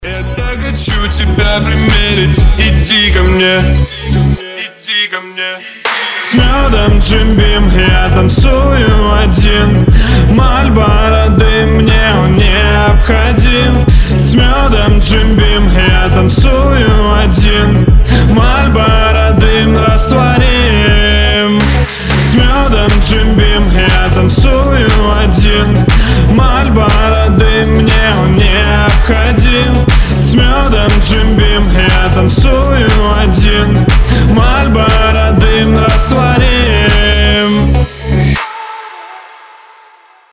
• Качество: 320, Stereo
клубняк
house